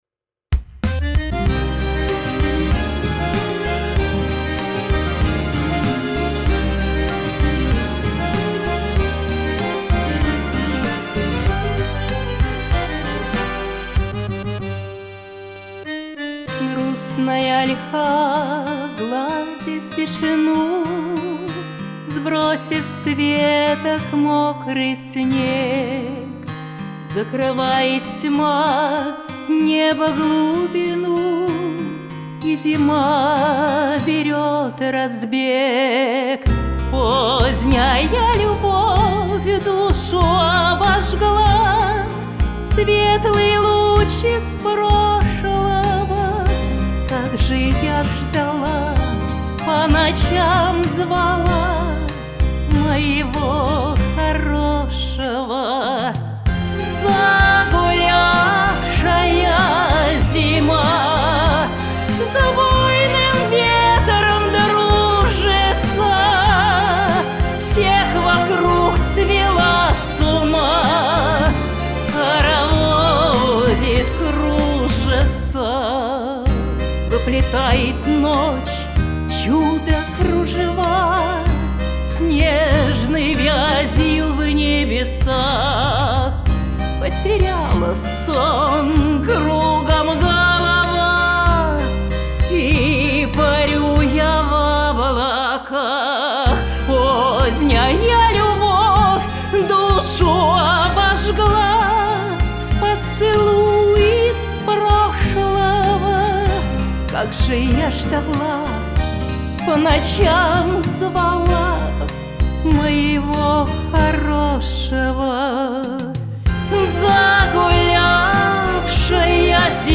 профессиональный женский вокал